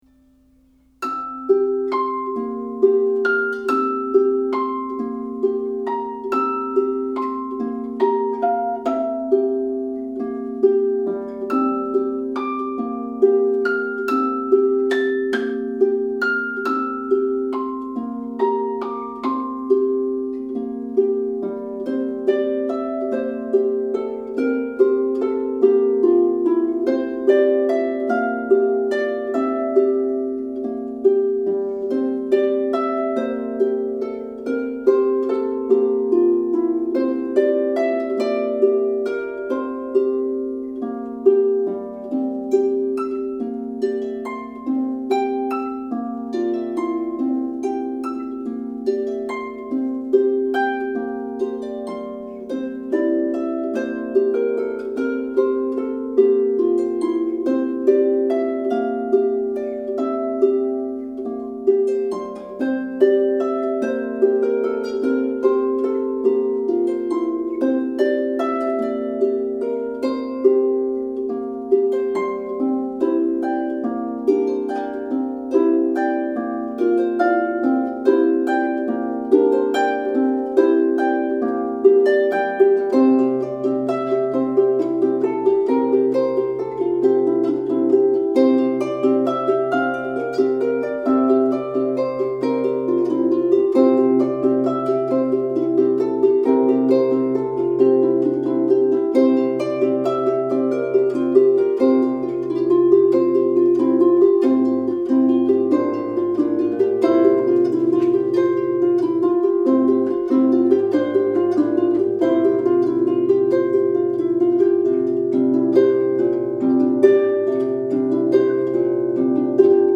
is for solo lever or pedal harp